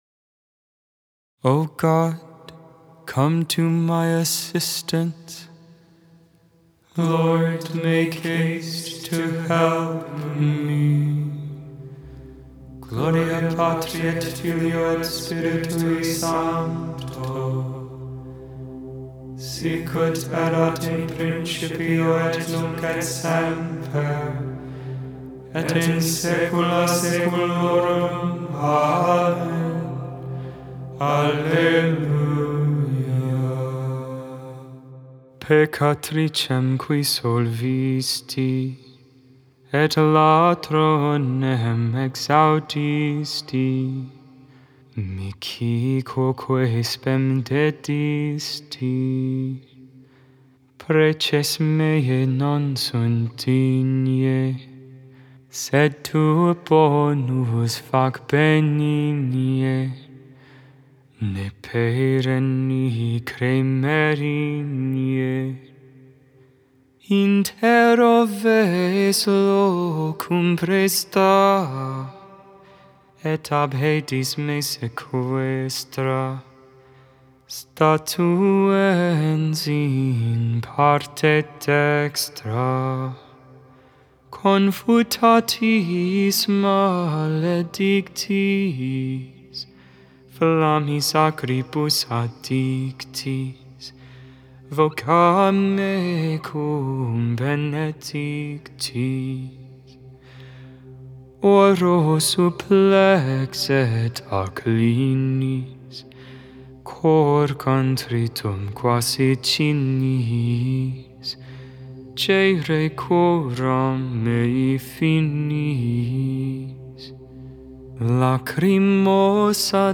Vespers, Evening Prayer for the 34th Friday in Ordinary Time, November 29, 2024.